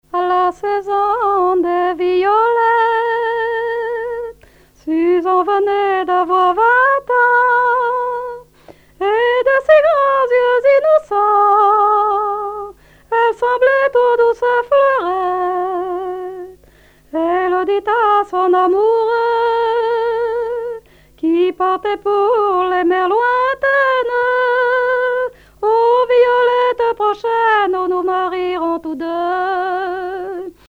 Genre strophique
collecte en Vendée
chansons traditionnelles
Pièce musicale inédite